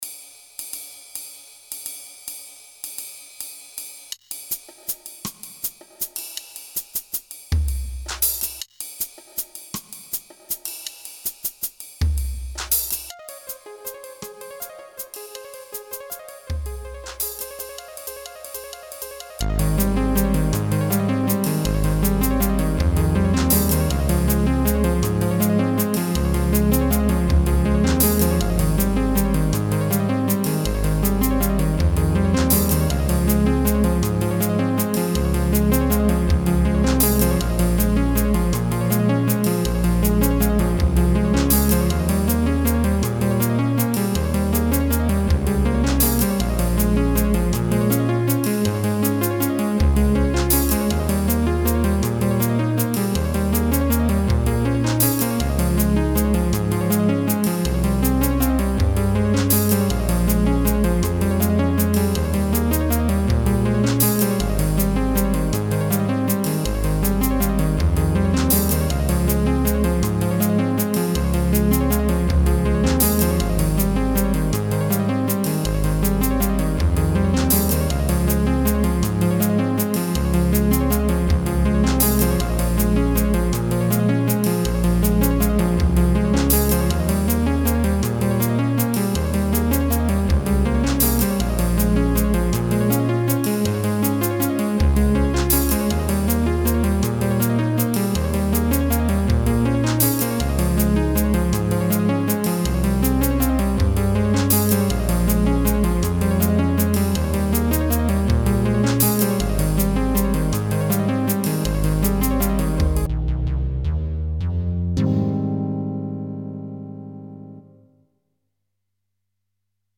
experimental edit